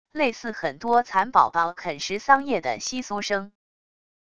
类似很多蚕宝宝啃食桑叶的窸窣声wav音频